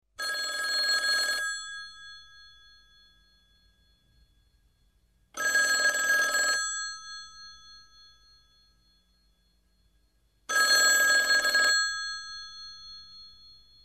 Android, Klassisk, Klassisk Telefon